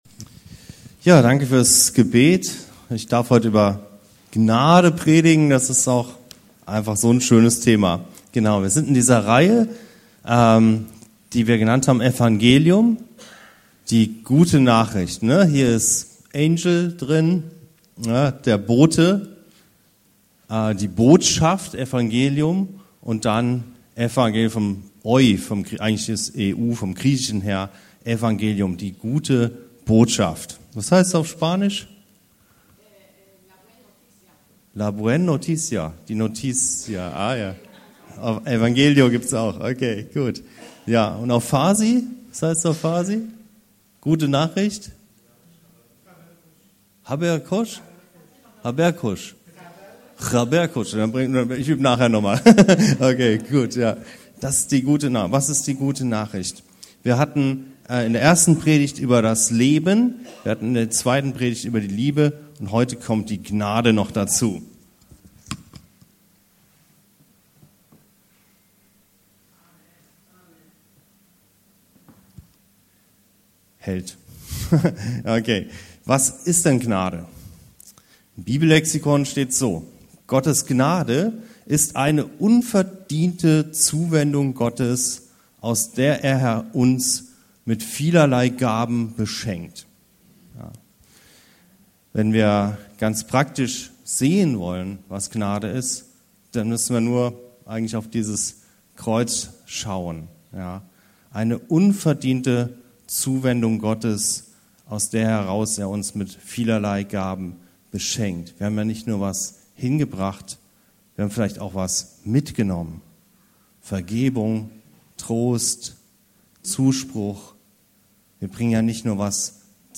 In der dritten Predigt zur Predigtreihe „EVANGELIUM“ geht es um die Gnade – wie können wir sie finden-entfalten- und weitergeben?